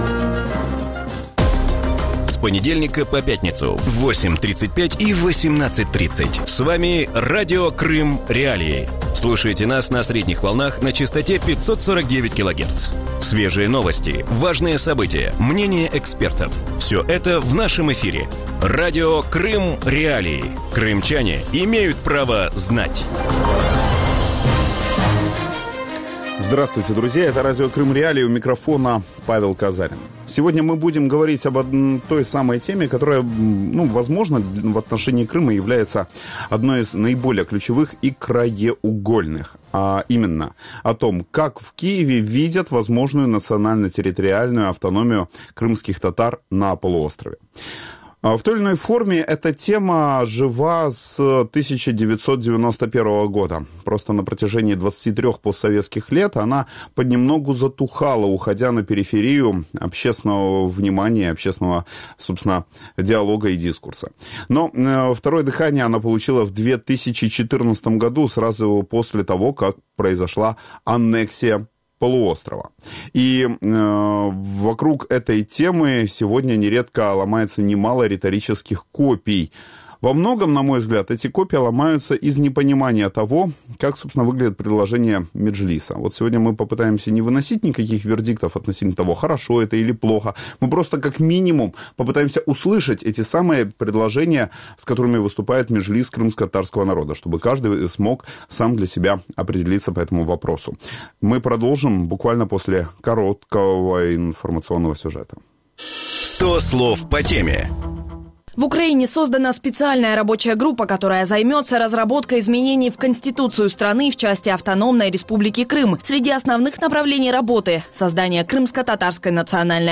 Представники кримськотатарського народу в деокопованому Криму повинні займатися вирішенням земельних питань і питань природокористування. Про це в ефірі Радіо Крим.Реалії розповів народний депутат України, голова Меджлісу кримськотатарського народу Рефат Чубаров.